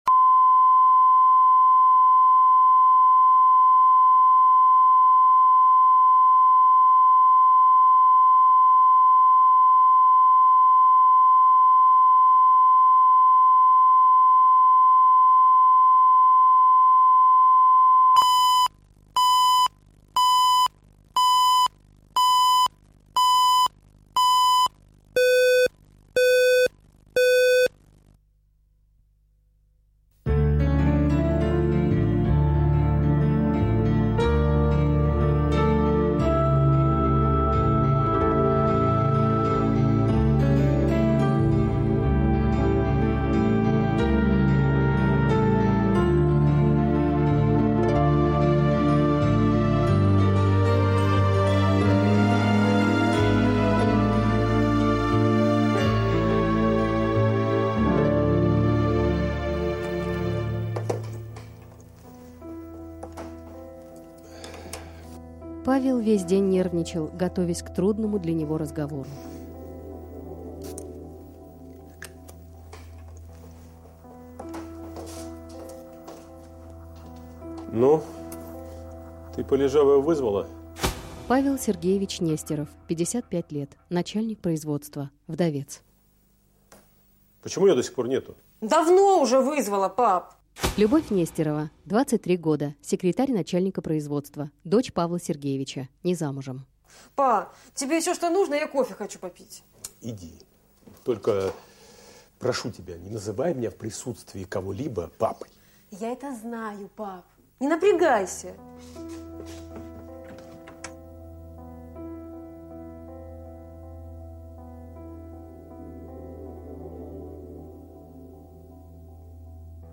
Аудиокнига Цветы на снегу | Библиотека аудиокниг
Прослушать и бесплатно скачать фрагмент аудиокниги